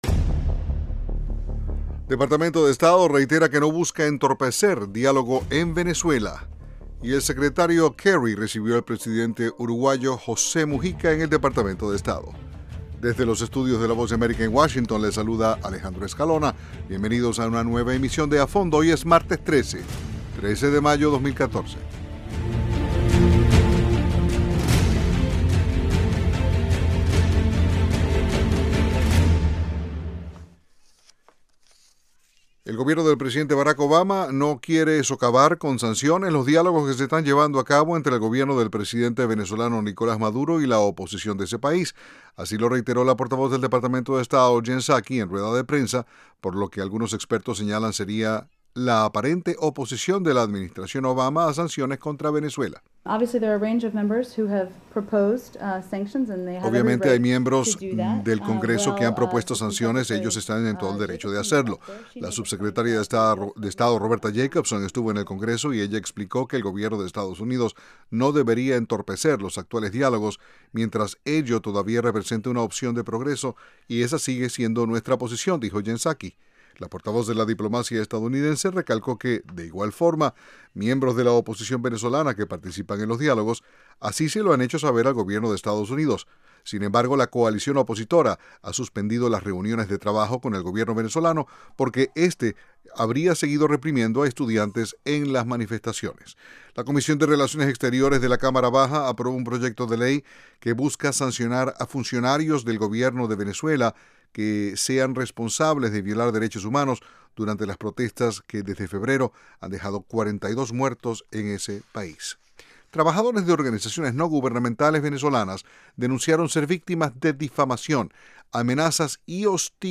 De lunes a viernes, a las 8:00pm [hora de Washington], un equipo de periodistas y corresponsales analizan las noticias más relevantes.